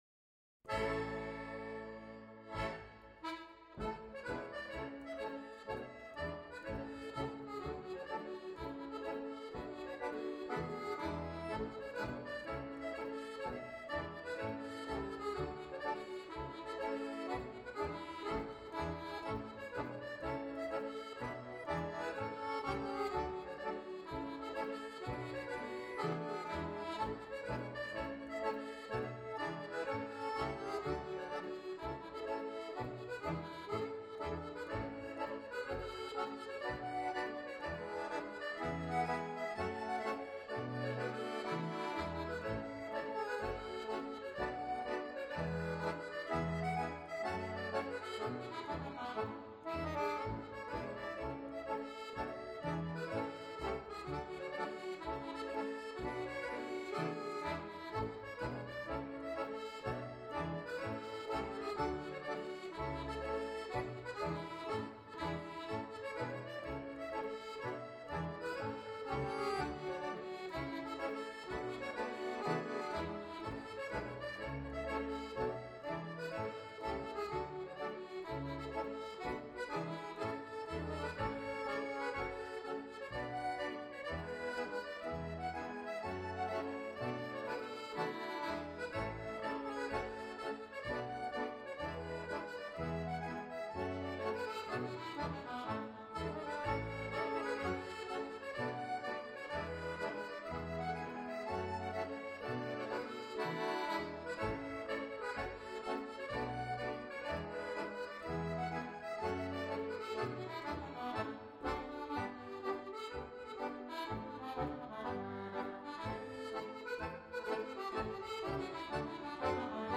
56-bar Strathspey
2×56 bar Strathspey
Accordion, Piano and Bass